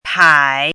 “迫”读音
pǎi
国际音标：pʰo˥˧;/pʰĄi˨˩˦